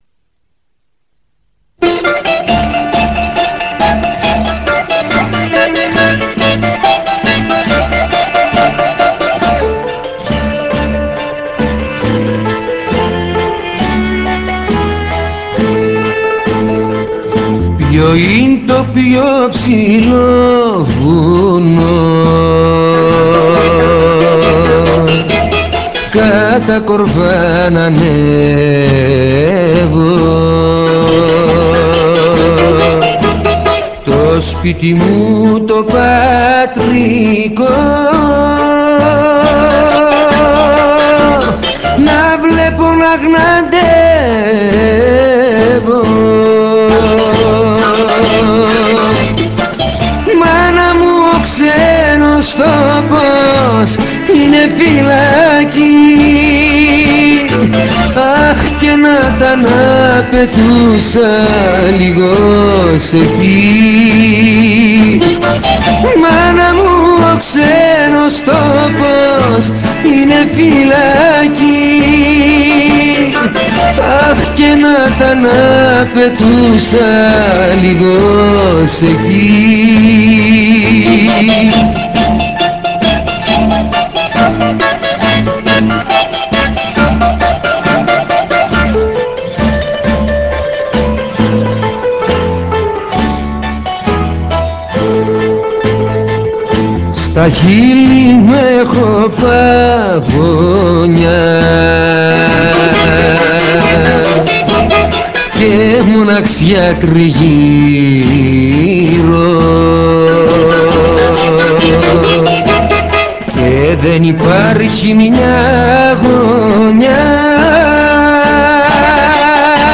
Zeimbekiko rhythm